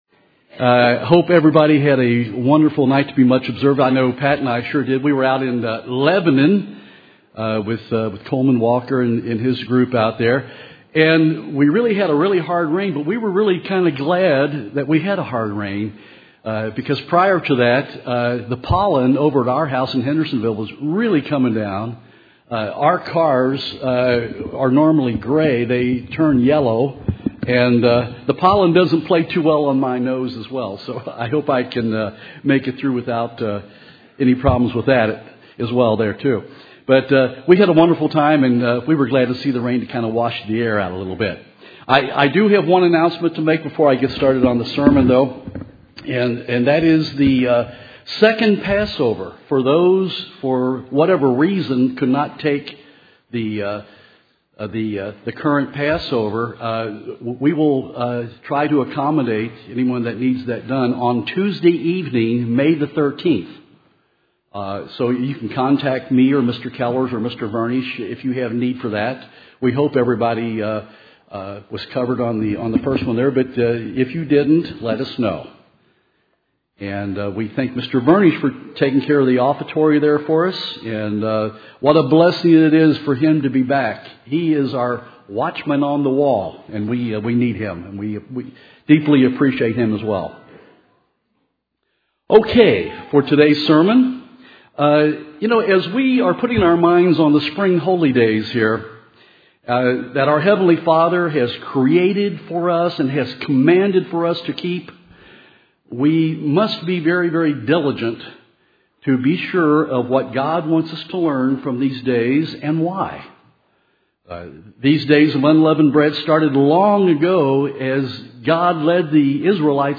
This sermon was given for the First Day of Unleavened Bread.